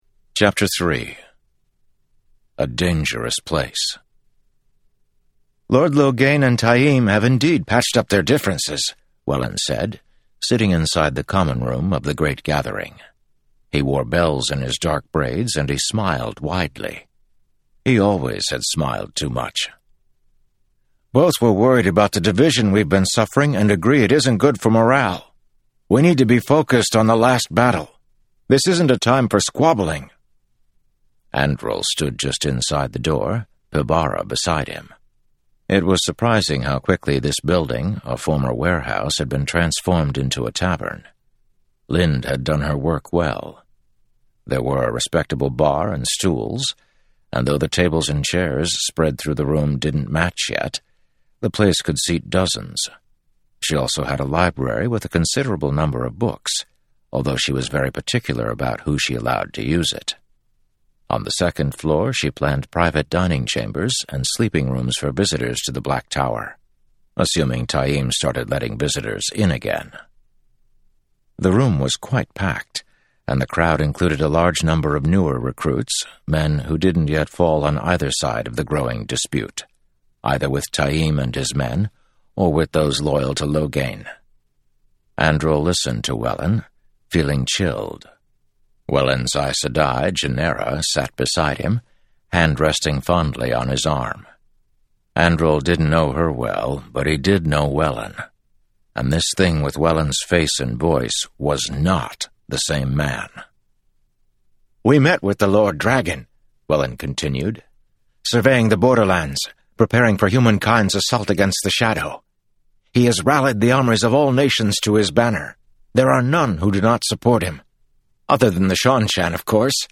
She wondered if I’d be willing to share a clip of the audiobook with my readers.
I’d been saying Mazrim Taim as “TAME,” but it’s “tah-EEM.”